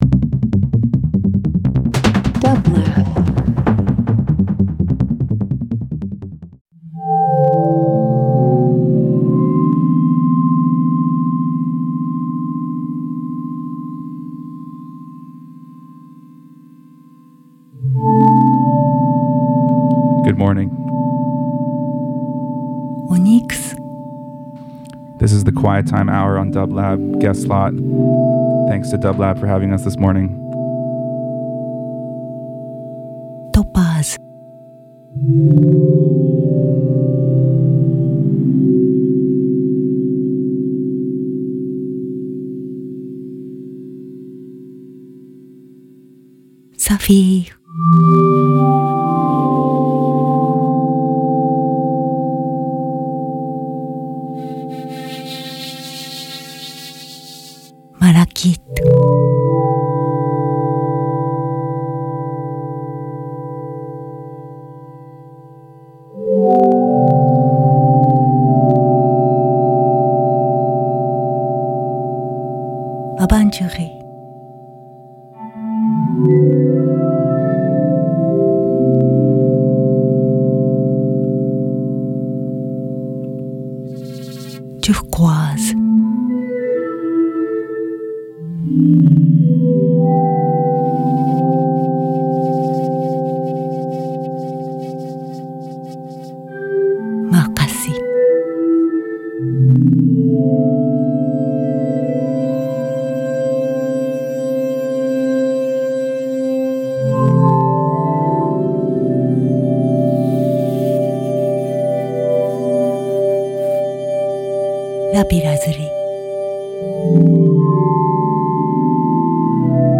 meditative music experiences